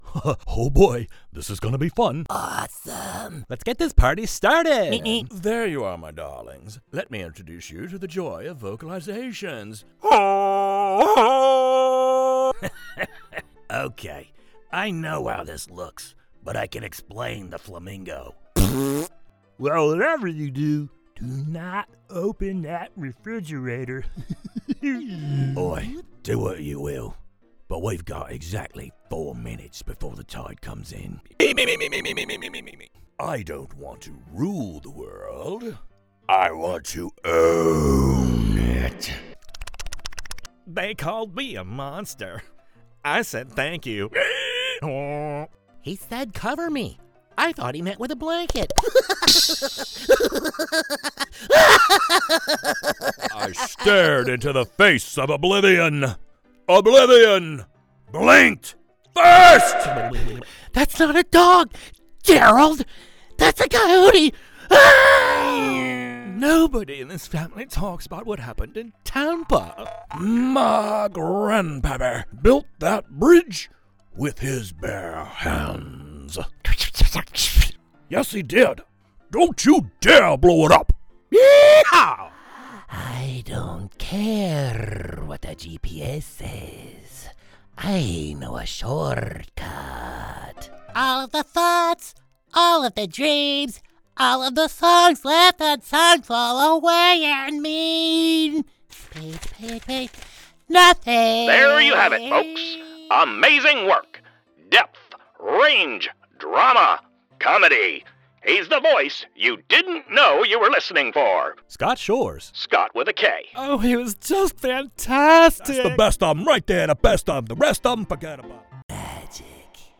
Animation Demo
Specializing in audiobook narration and animated character work, I bring a warm, rich baritone with a wide character range — from wide-eyed children and creatures of every size and shape, to gruff military veterans.